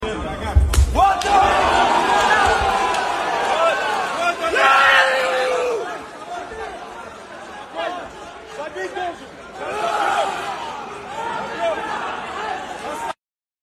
🤜 Hardcore Knockout 🦵 💢🤛💥 sound effects free download